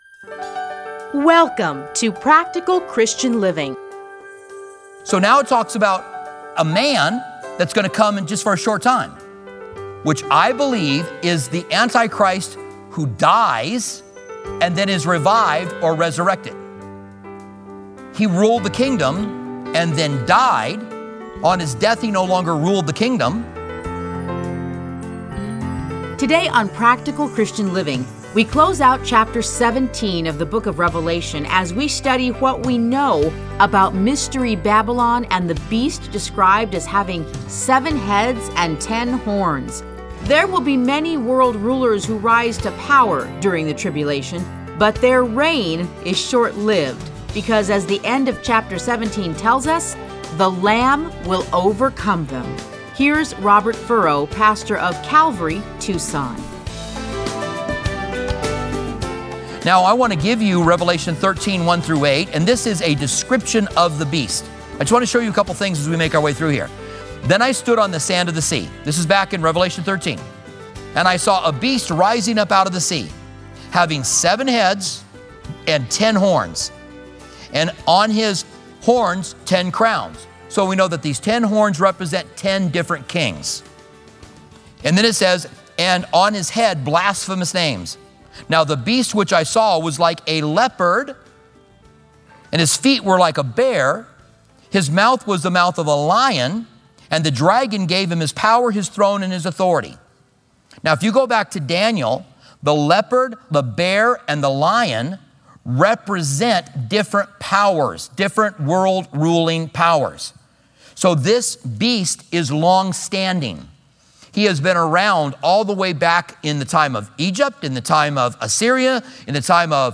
Listen to a teaching from Revelation 17:7-18.